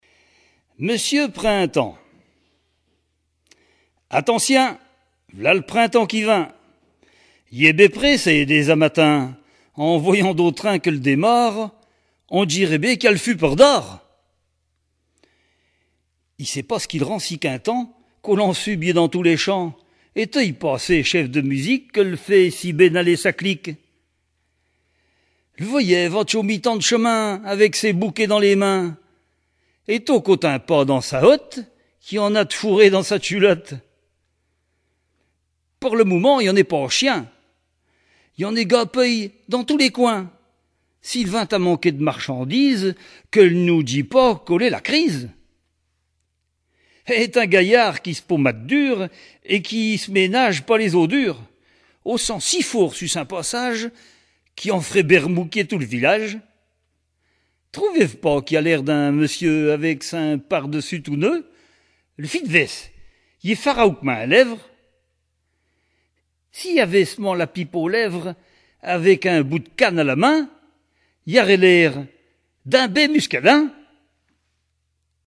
Langue Patois local
Genre poésie
Catégorie Récit